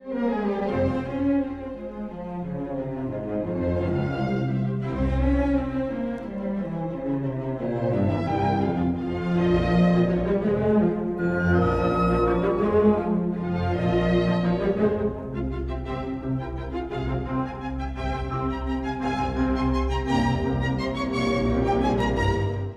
バイオリンの高まる旋律は、エグモントによる説得。
そこからは長調に転調。